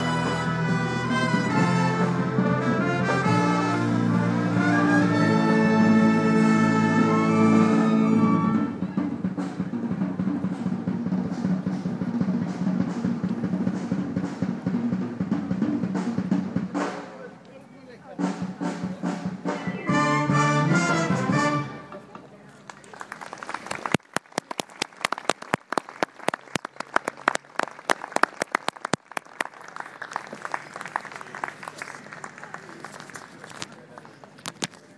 Final moments of final piece of first set